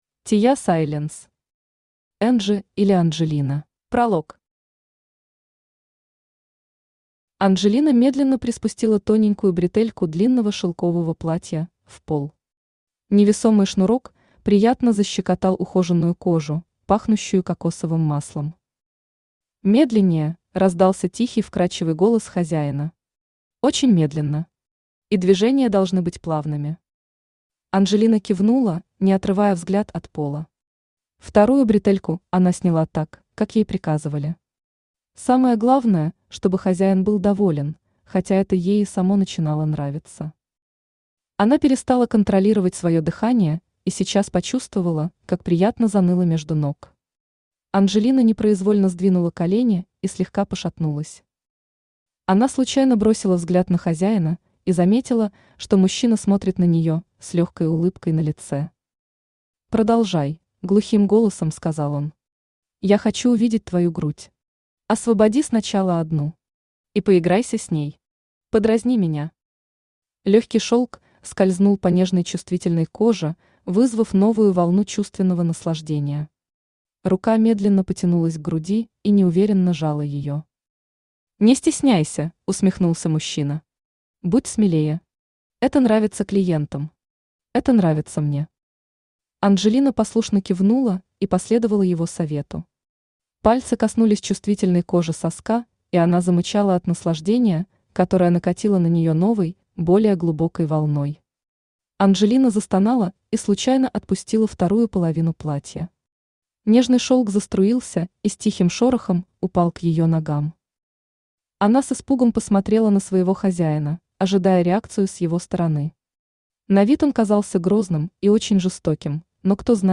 Аудиокнига Энджи или Анджелина | Библиотека аудиокниг
Aудиокнига Энджи или Анджелина Автор Тея Сайленс Читает аудиокнигу Авточтец ЛитРес.